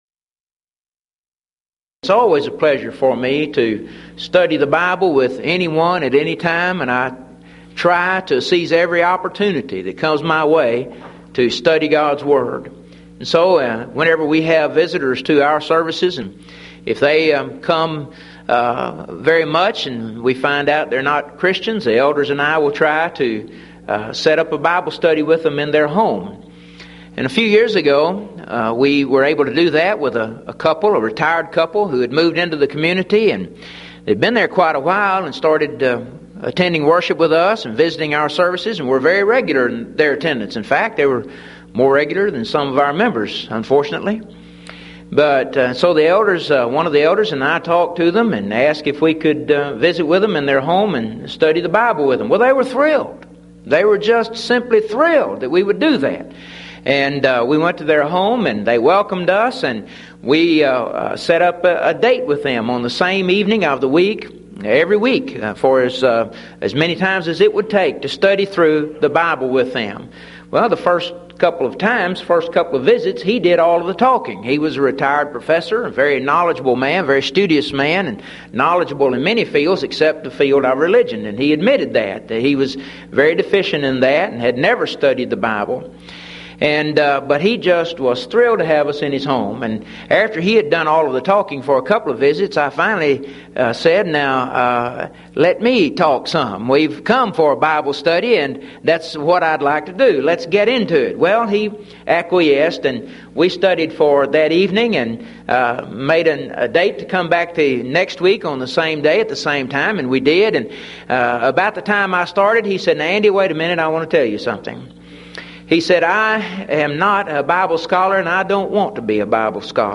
Event: 1994 Mid-West Lectures
Filed Under (Topics): Preaching